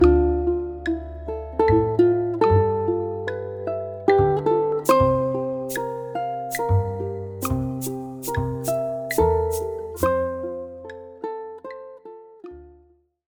The dotted half note tied to a half note in the second line receives a total of five beats.
tied notes